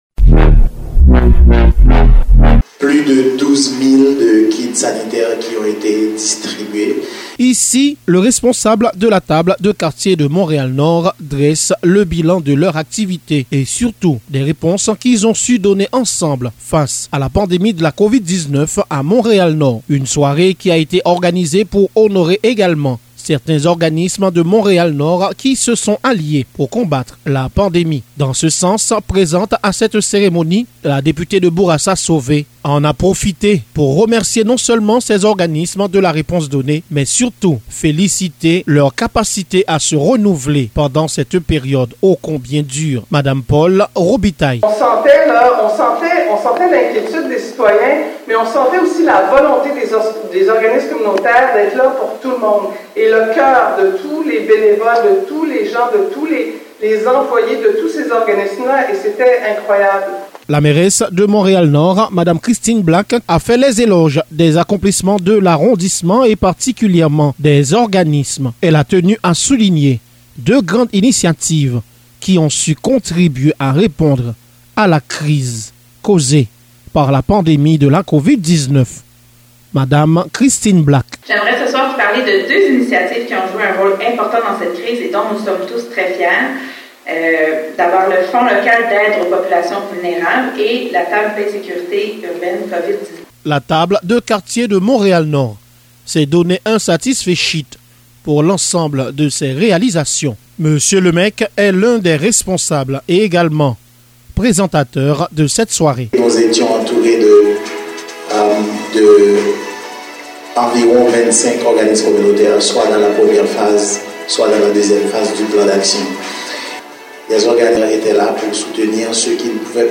REPORTAGE-table-des-quartiers-mtl-nord.mp3